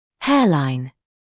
[‘heəlaɪn][‘хэалайн]линия волос
hairline.mp3